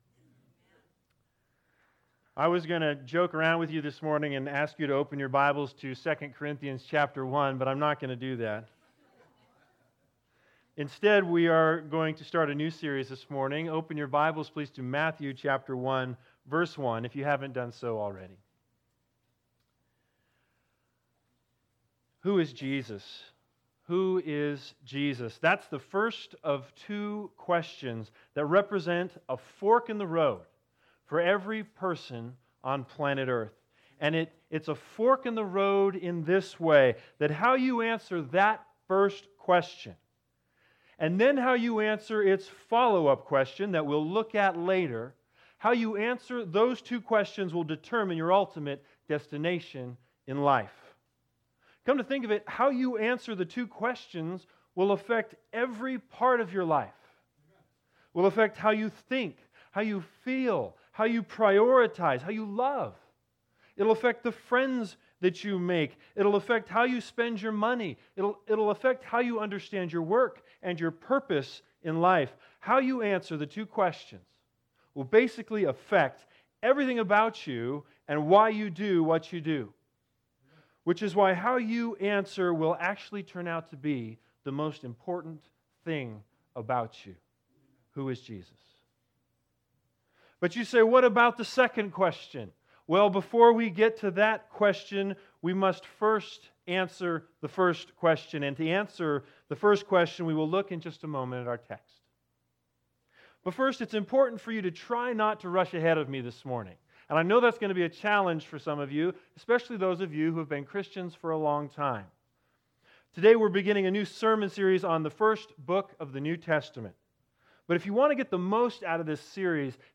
Service Type: Sunday Sermons